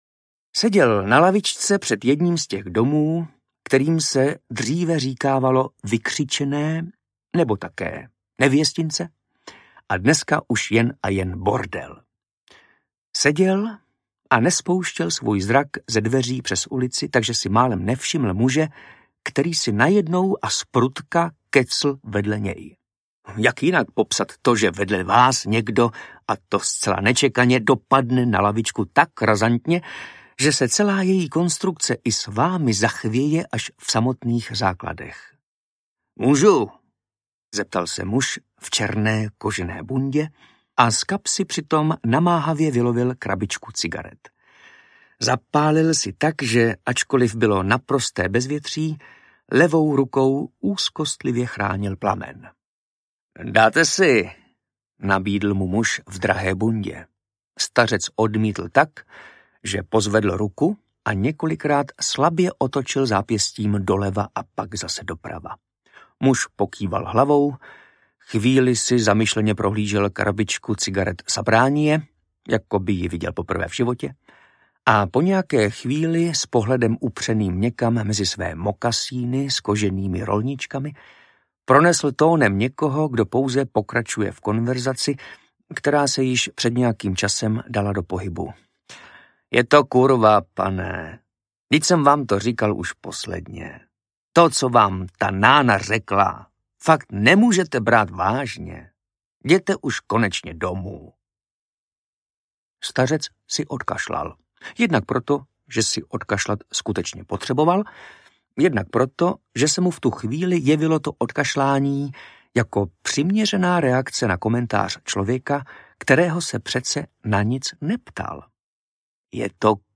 Láska je kurva a jiné povídky audiokniha
Ukázka z knihy
• InterpretMartin Myšička